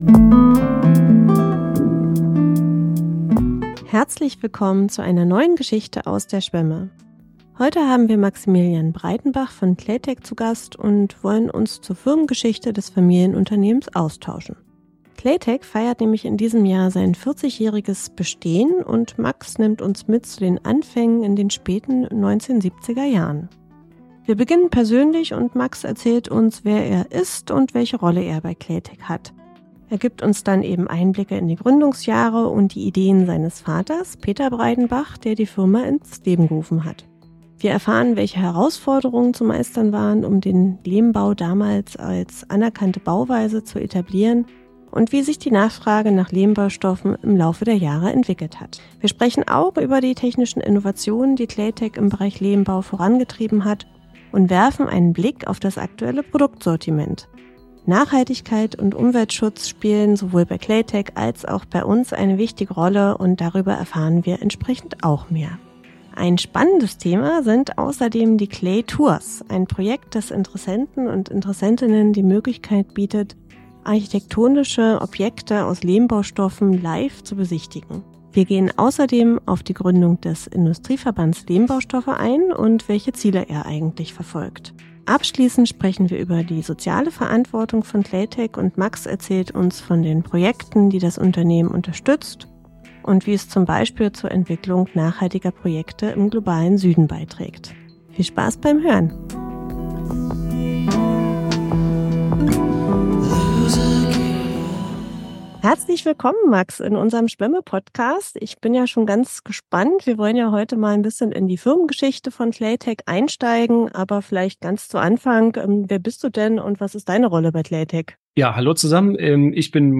im Gespräch ~ Geschichte(n) aus der Schwemme Podcast